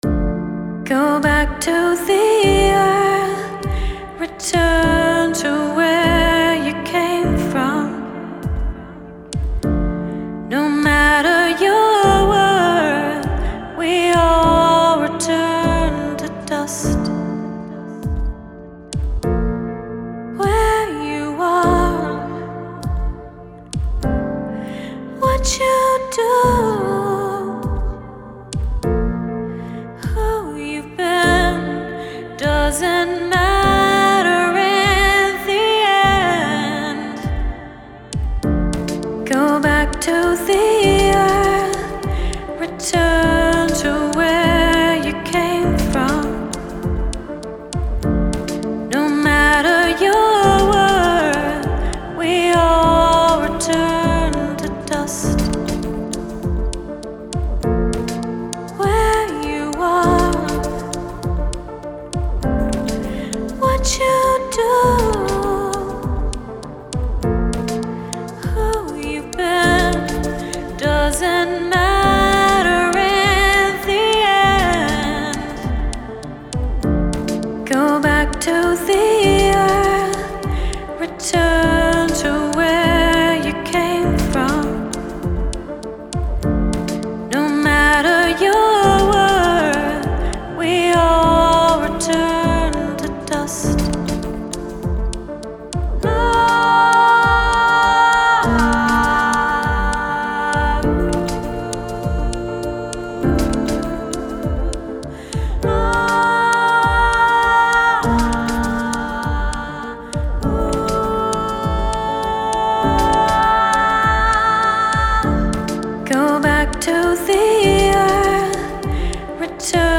but i think bigger beats would work better than small clicky percussion.
great vocals, instant classic.